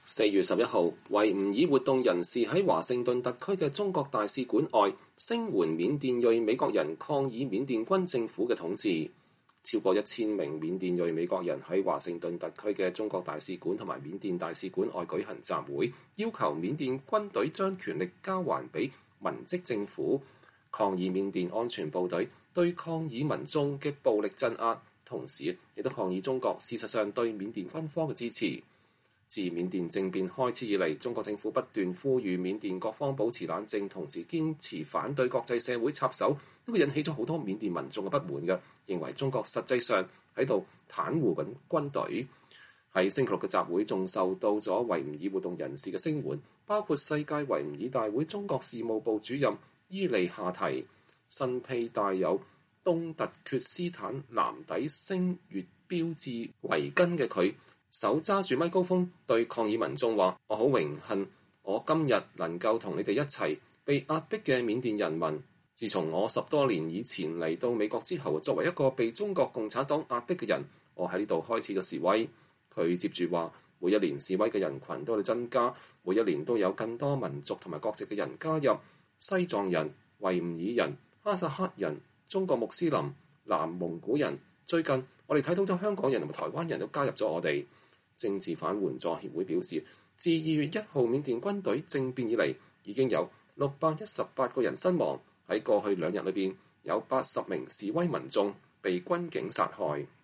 4月11日，維吾爾活動人士在華盛頓特區的中國大使館外，聲援緬甸裔美國人抗議緬甸軍政府的統治。